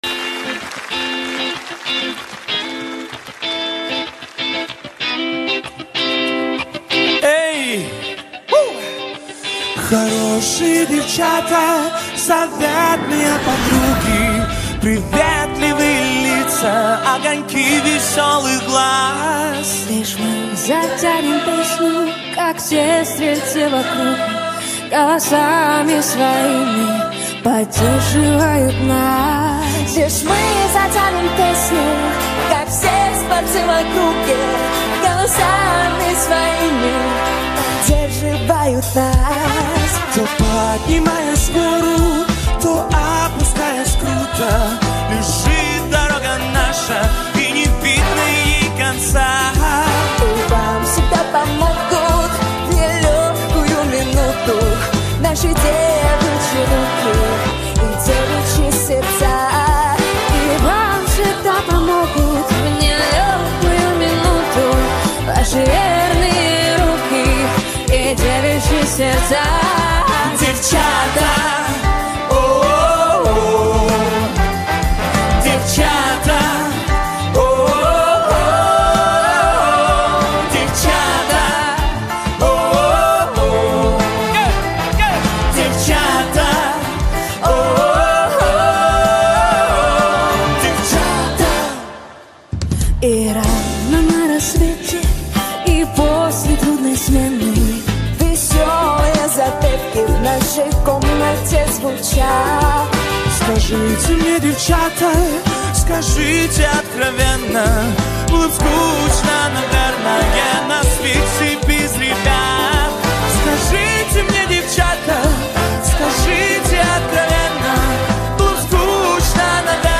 Гала-концерт от 06.12.2024г.